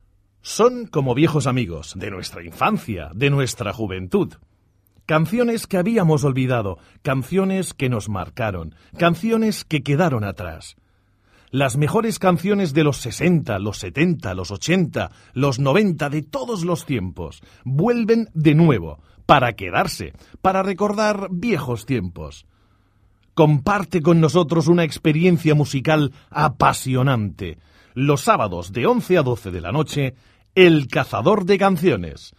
Voz grave versátil, dulce y natural, y a veces fuerte.
kastilisch
Sprechprobe: Sonstiges (Muttersprache):
Versatile voice, sweet and warm, and sometimes strong